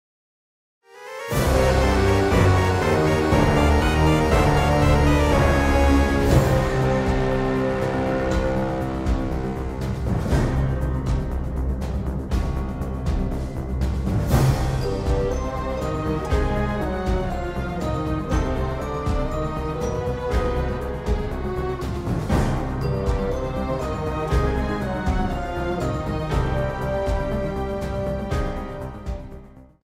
Cropped to 30 seconds with fade-out